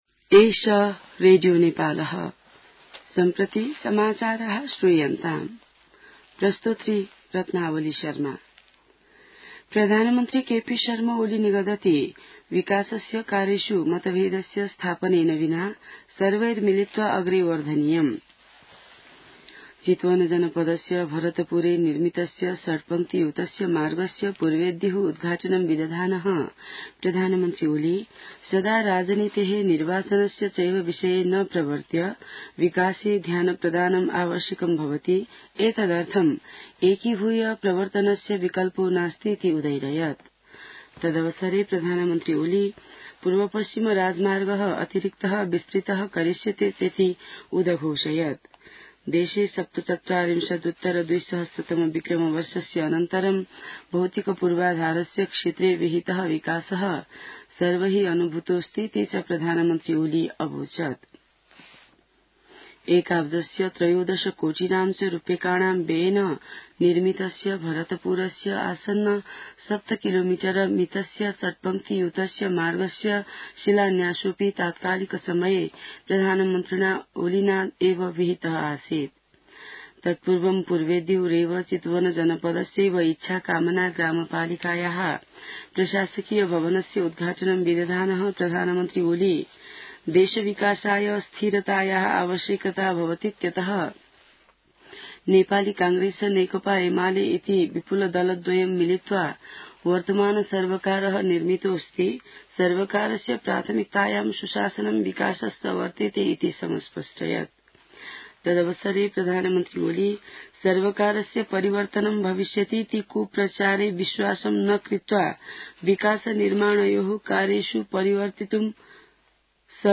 संस्कृत समाचार : ६ वैशाख , २०८२